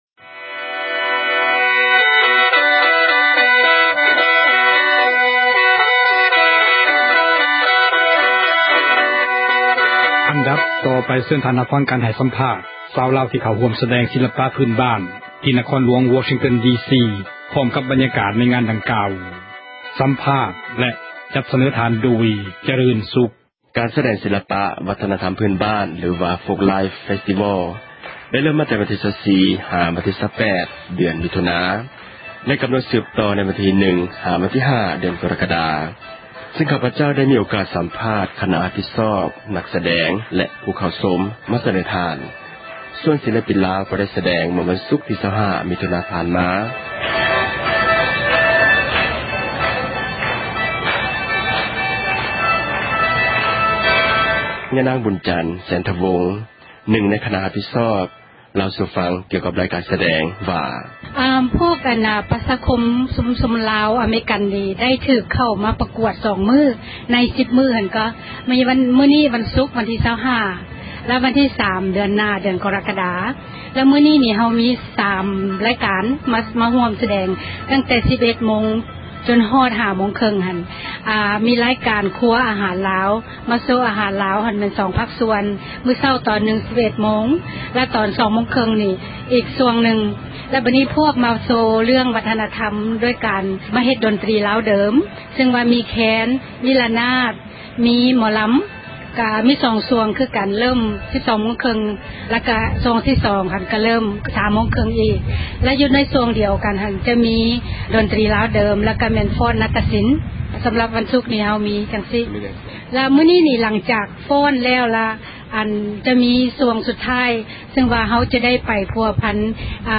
ເຊີນທ່ານຮັບຟັງ ການສັມພາດ ຊາວລາວ ທີ່ເຂົ້າຮ່ວມສະແດງ ສິລປະ ພື້ນບ້ານ ທີ່ນະຄອນຫລວງ ວໍຊິງຕັນ ດີຊີ ພ້ອມກັບ ບັນຍາກາດ ໃນງານ ດັ່ງກ່າວ…